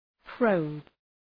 probe Προφορά
{prəʋb}